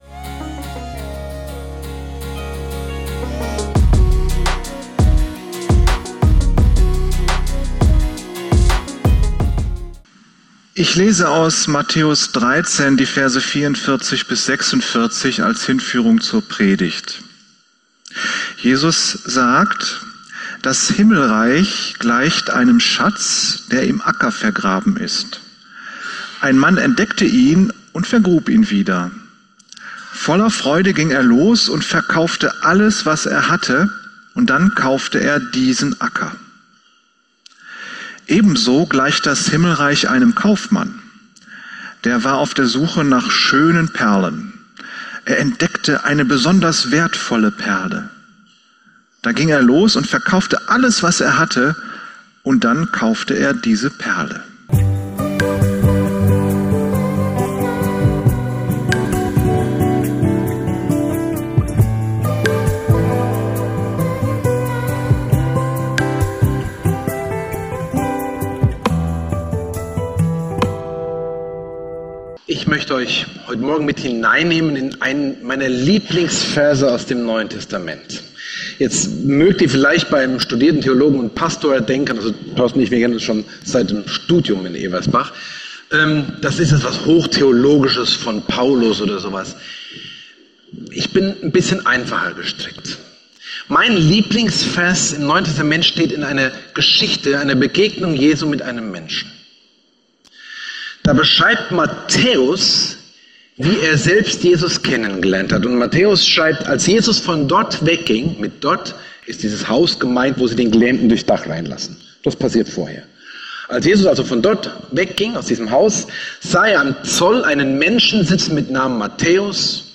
Du bist die Perle ~ Geistliche Inputs, Andachten, Predigten Podcast